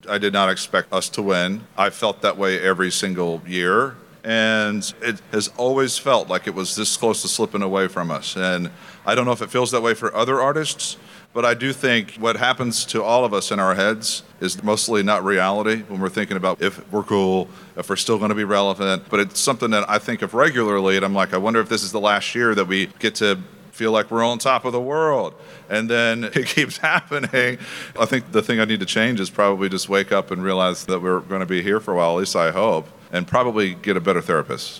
Backstage, TJ told reporters that he truly did not think they’d win the award this year and how he needs to get out of his head at times.
Audio / Backstage at this year's CMA Awards, TJ Osborne says he really didn't think they'd win for CMA Vocal Duo of the Year.